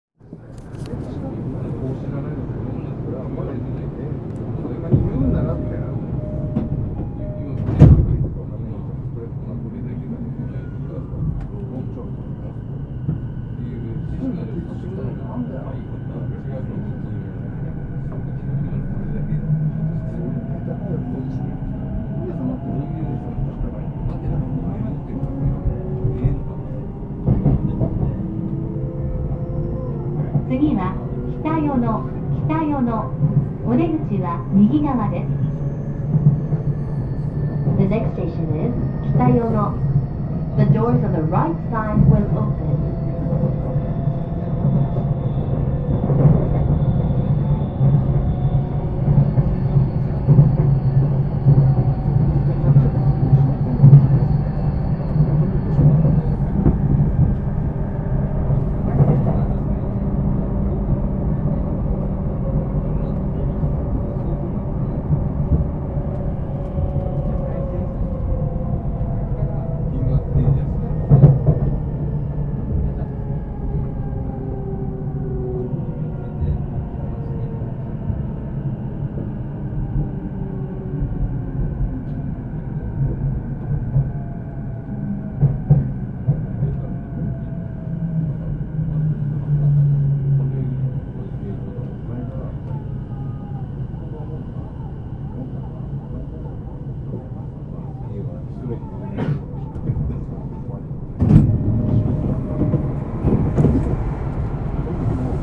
走行音
録音区間：与野本町～北与野(お持ち帰り)